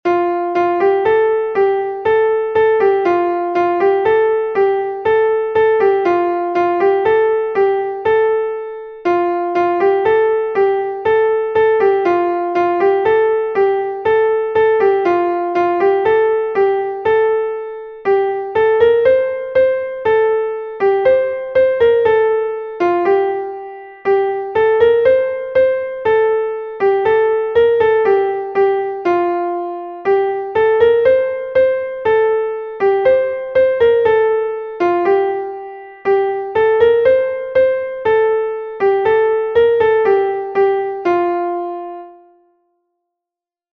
a Hanter dro from Brittany